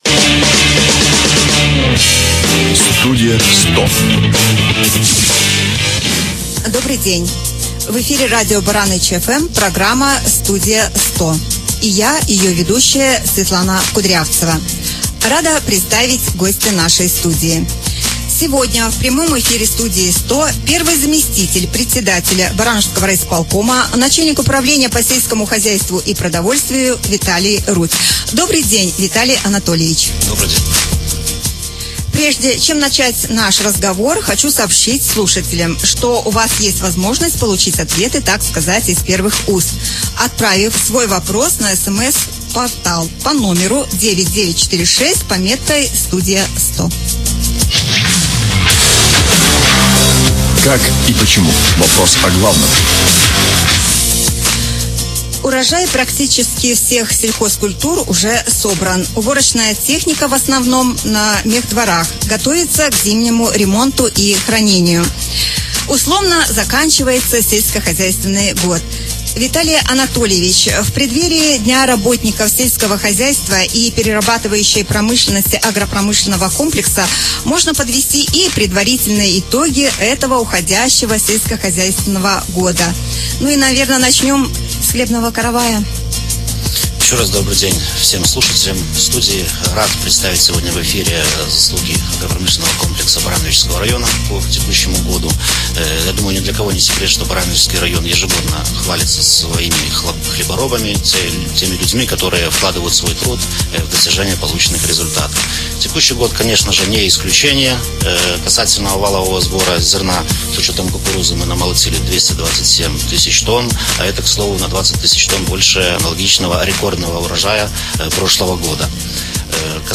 Гость «Студии 100» — первый заместитель председателя Барановичского райисполкома — начальник управления по сельскому хозяйству и продовольствию Виталий Рудь.